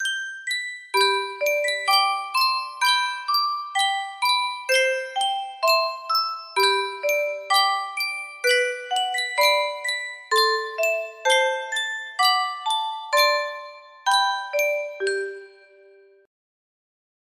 Sankyo Music Box - Michael Row the Boat Ashore FPW music box melody
Full range 60